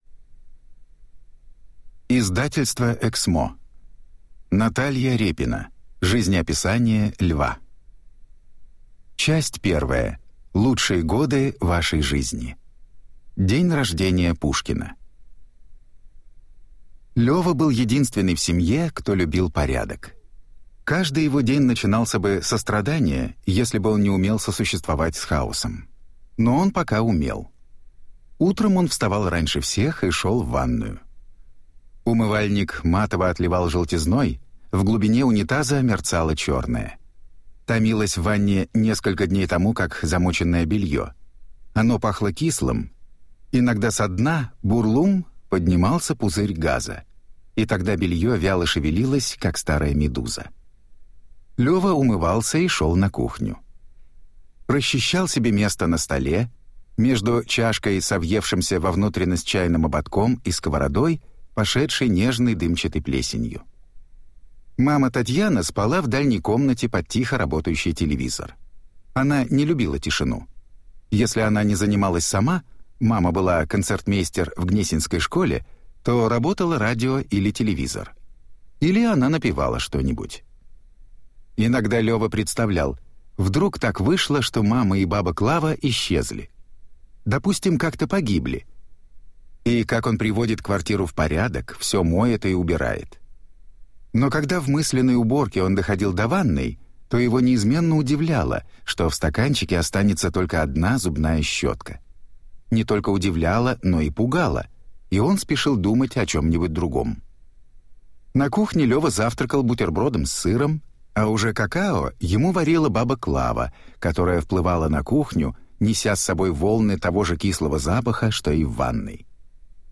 Аудиокнига Жизнеописание Льва | Библиотека аудиокниг
Прослушать и бесплатно скачать фрагмент аудиокниги